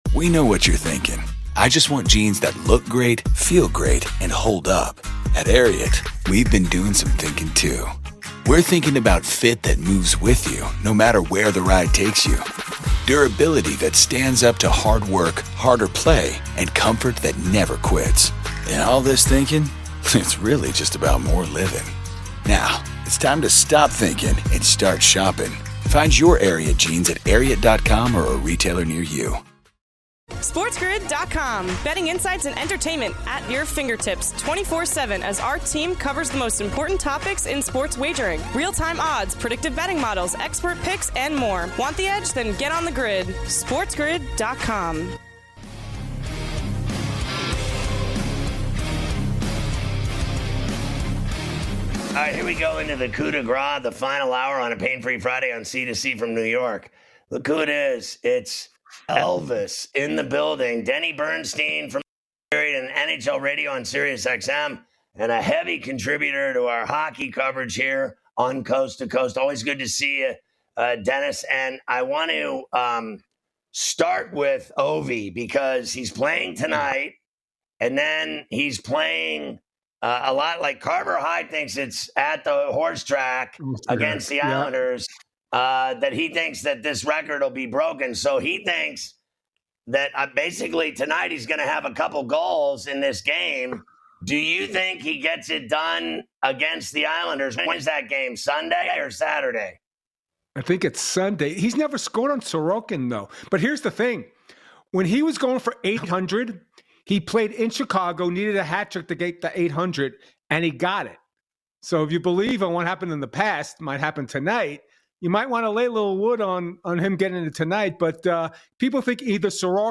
Legendary sports shock jock
his “in your face” style